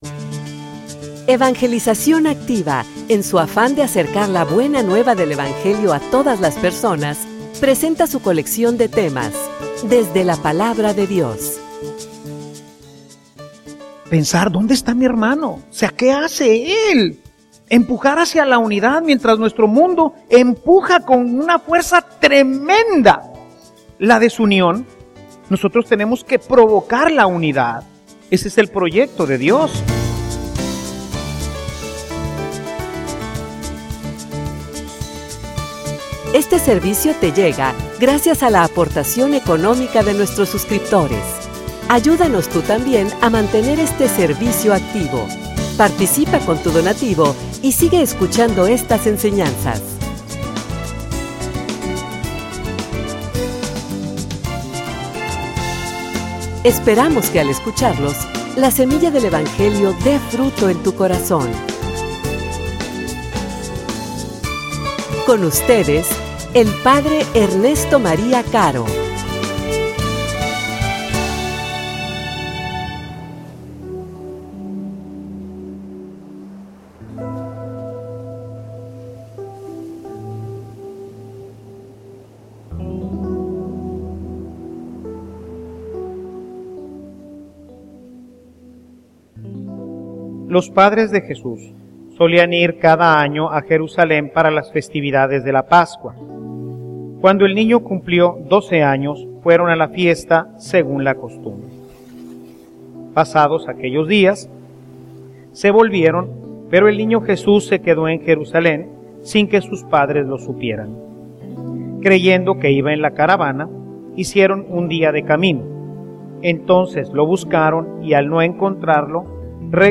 homilia_Donde_esta_tu_hermano.mp3